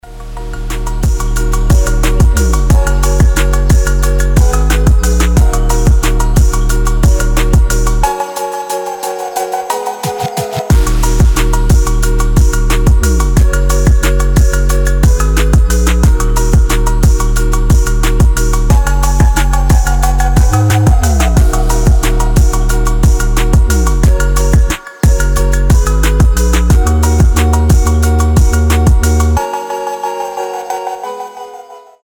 • Качество: 320, Stereo
мелодичные
мощные басы
без слов
Атмосферная битовая музыка